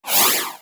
Sci Fi Robot Screech